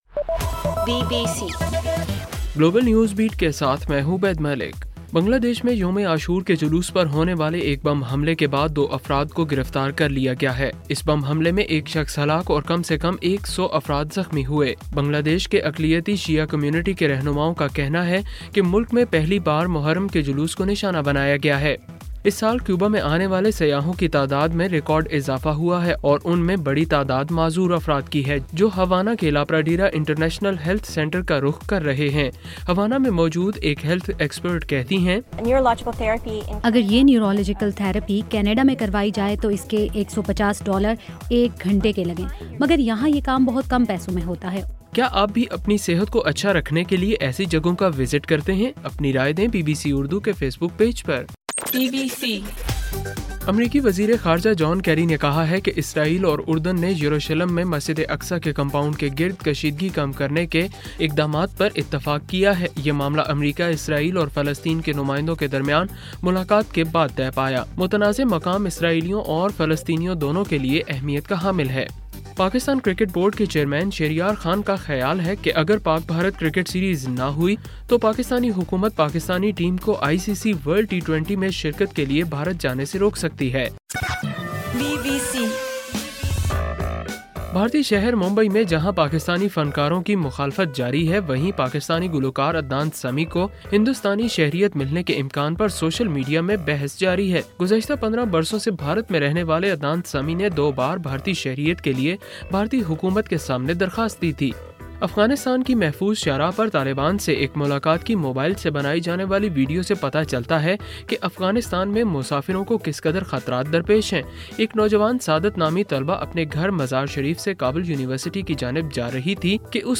اکتوبر 24: رات 12 بجے کا گلوبل نیوز بیٹ بُلیٹن